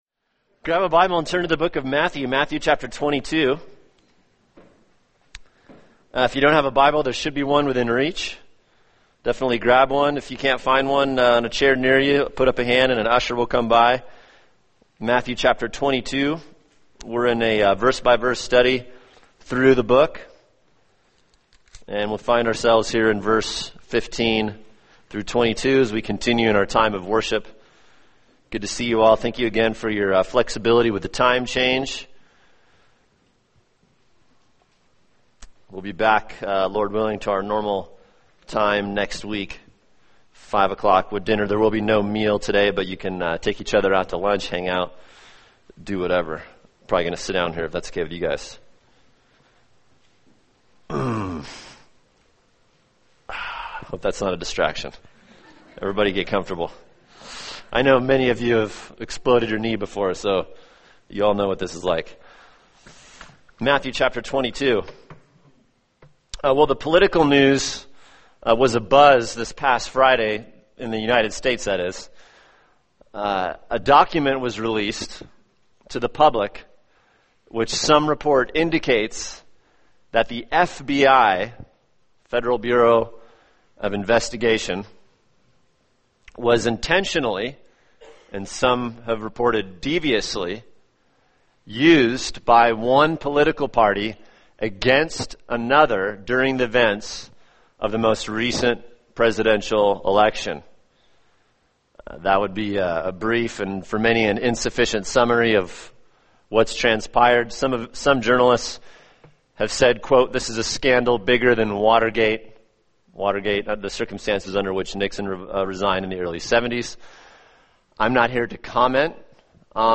[sermon] Matthew 22:15-22 – Jesus, Taxes, and Government | Cornerstone Church - Jackson Hole